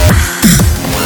no, they don't need to be ms-perfect, they can be slightly offset by a few ms in either direction (as would be the case when playing) and still cause noticeable distortion. i made some examples here: